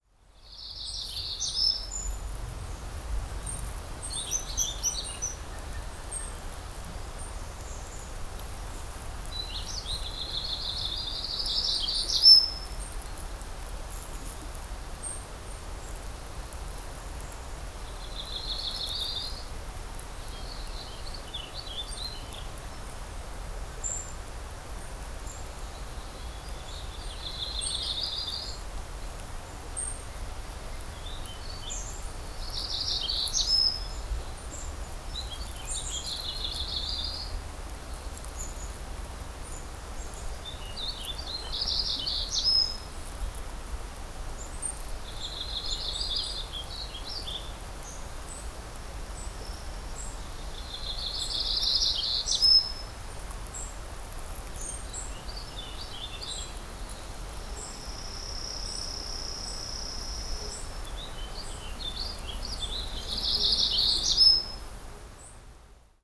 This recording, also captured with my cellphone, features a Yellow-rumped Warbler, a Warbling Vireo, a Brown Creeper (maybe), a Black-throated Gray Warbler, and maybe a Pacific-slope Flycatcher.
Kitty-Coleman-Birdsongs.wav